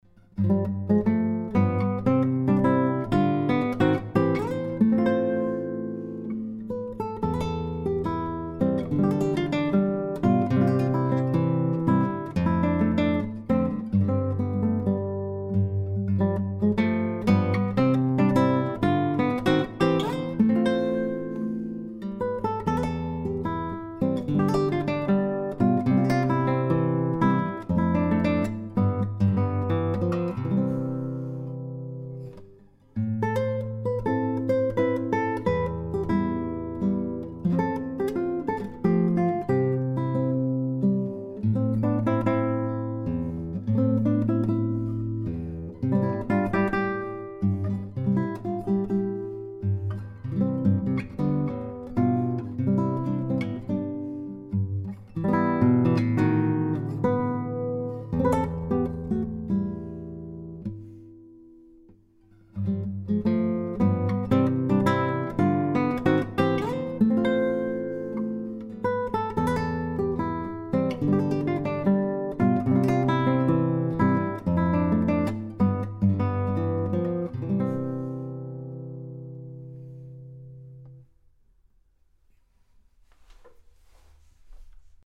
Mikrofon für klassische Gitarre
(beim Hall) Anhänge kurzerhall.mp3 3,2 MB · Aufrufe: 1.560